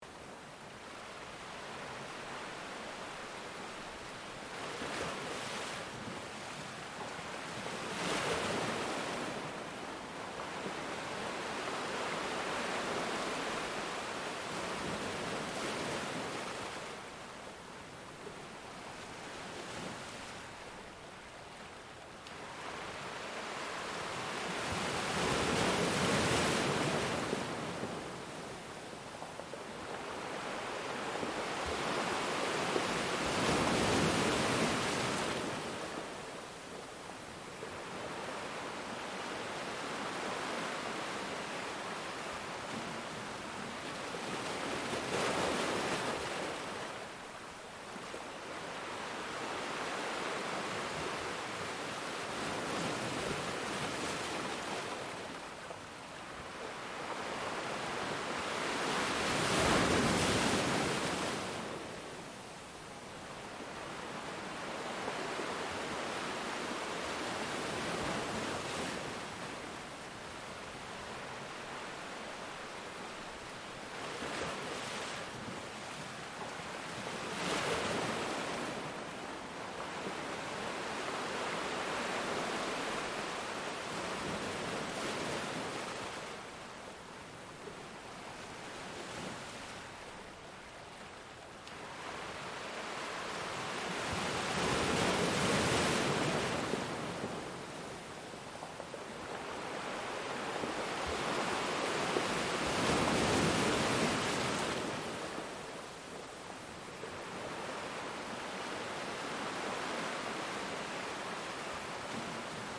Audio - Ocean
Nature Audio - No Distractions only Focus
ocean-mxB4WPq643uEjMOM.mp3